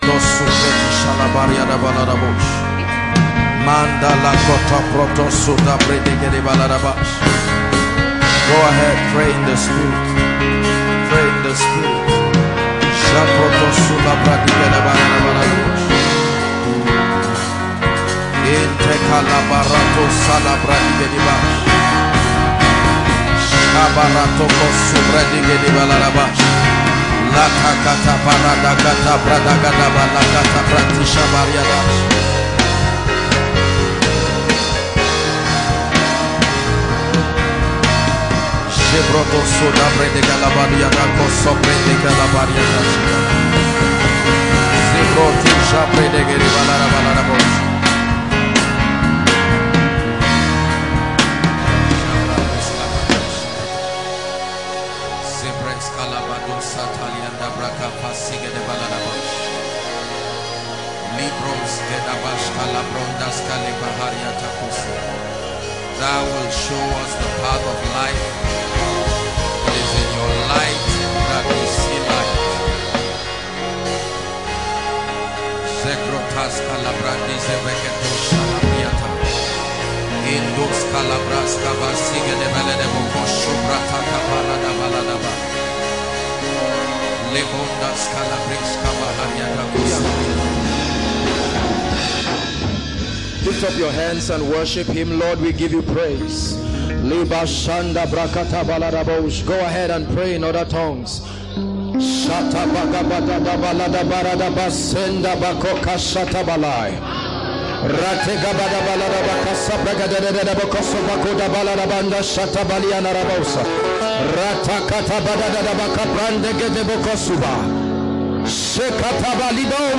New Sermon, Powerful Teaching and messages.